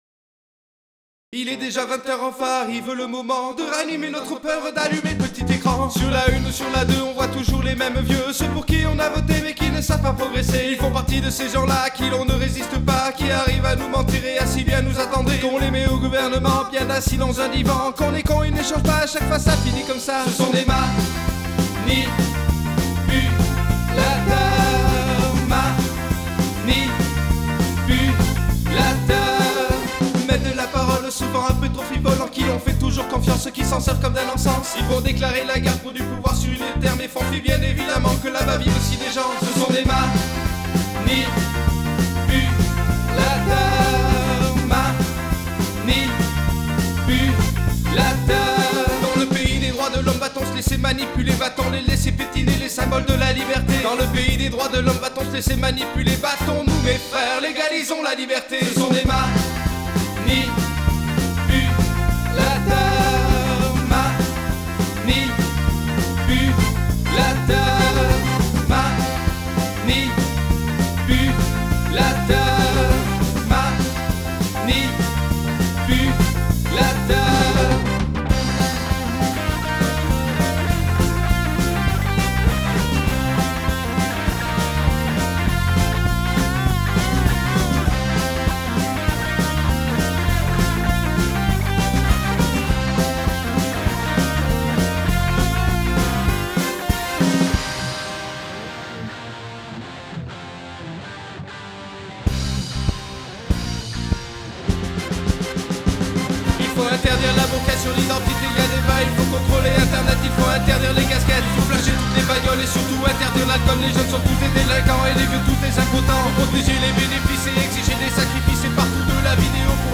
En effet ça met encore plus les voix en avant.
La batterie est plus claire.
la voix lead, le timbre est plutôt agréable à entendre
mais le tempo que tu utilises me parait un peu trop rapide pour ton débit d'élocution. résultat, tu ne respire pas ou peu, tu chantes en apnée, et tu n'as pas assez de jus (souffle) pour accentuer les moments qui mériteraient éventuellement de l'être.
la basse, tu la joues trop en l'air. elle sautille. tu me fais un zouk.